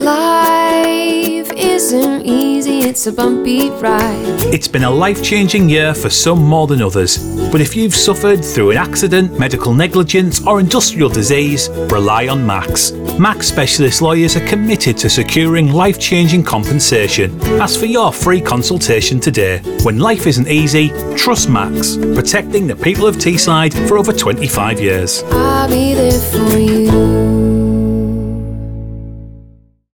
Our Radio Adverts Hear our latest media campaign across Metro & TFM Radio, Greatest Hits, Smooth, Galaxy, and Capital Radio: Personal Injury 30s Other Services 30s Generic Short 10s Personal Injury 10s